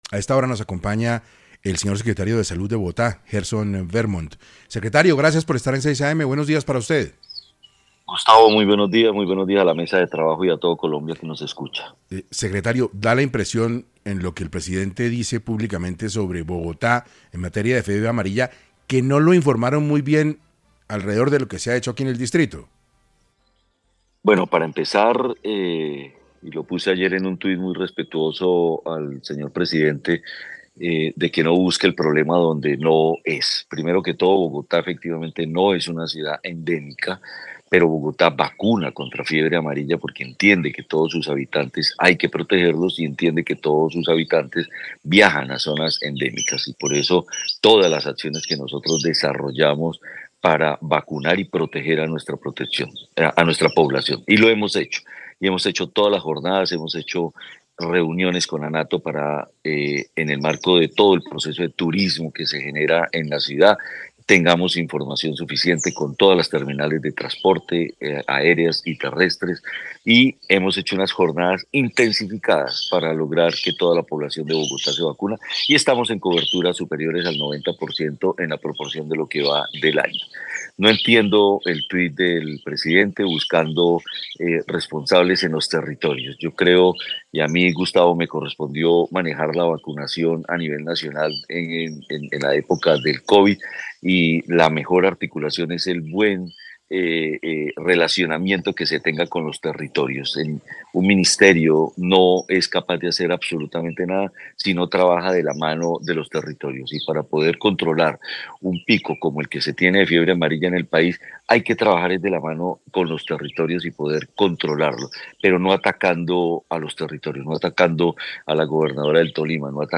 En entrevista con 6AM de Caracol Radio, el funcionario aclaró que Bogotá no es una zona endémica, aun así, se han aplicado numerosas vacunas especialmente para proteger a quienes viajan a regiones con circulación activa del virus.